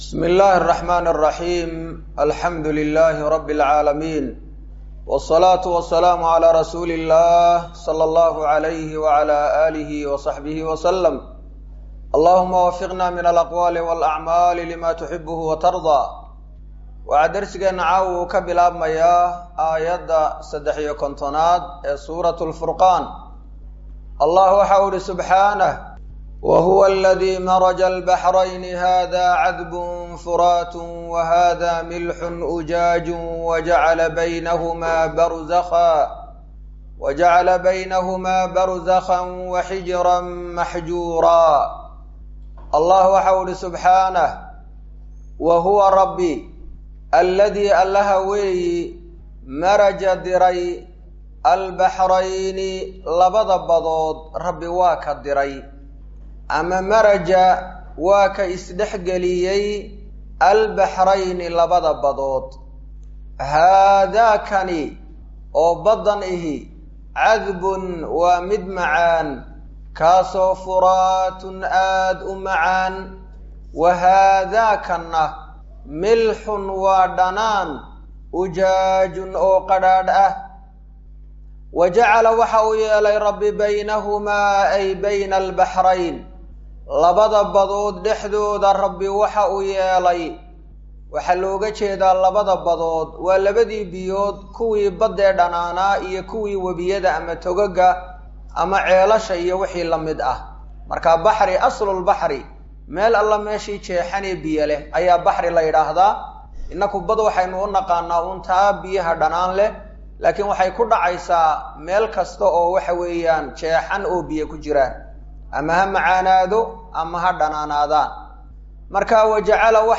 Waa Tafsiirka Qur’aanka Ee Ka Socda Masjid Ar-Rashiid – Hargaisa